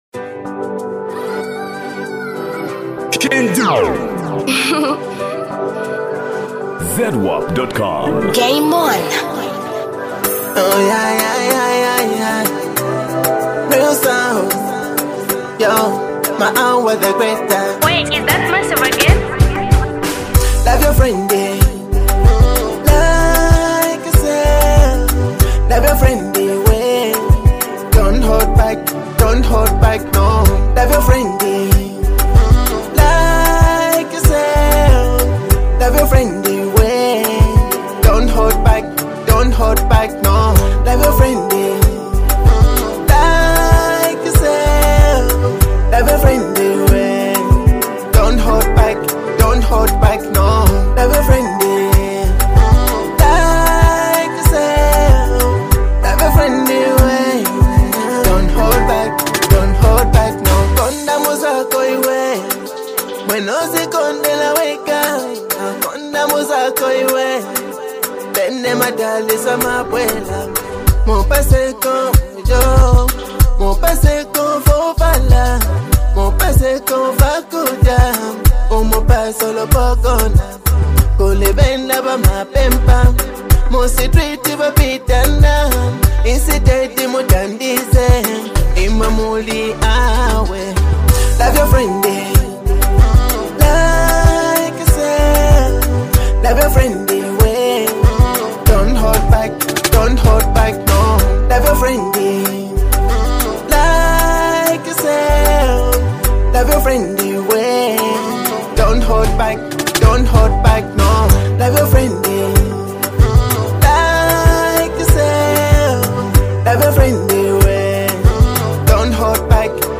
Latest Zambia Afro-Beats Single (2026)
Genre: Afro-Beats